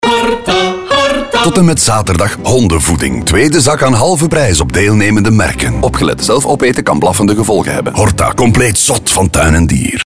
Vier radiospots die opnieuw de zintuigen prikkelen.